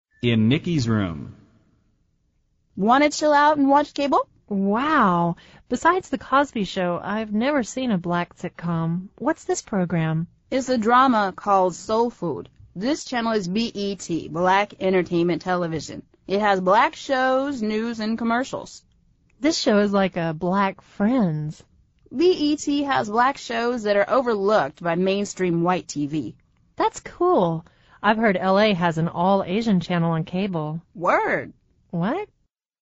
美语会话实录第232期(MP3+文本):Black sitcom